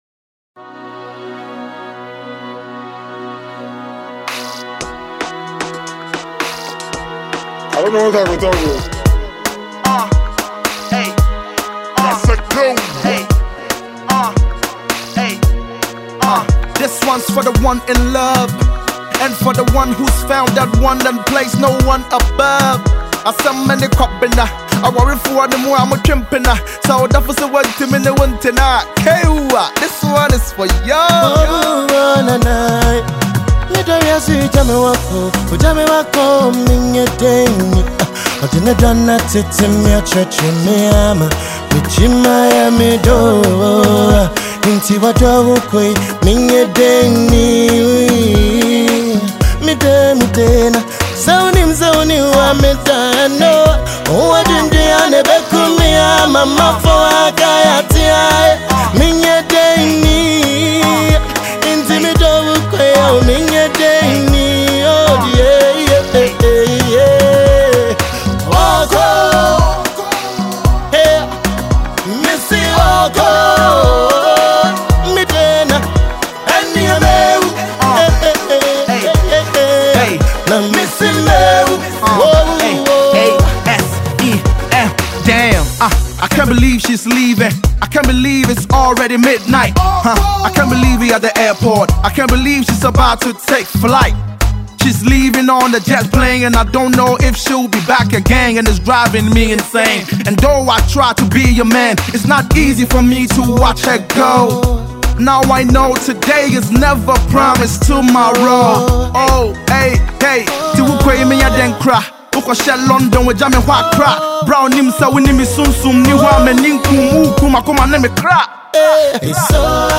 the award-winning Ghanaian rapper just might.
hearty vocals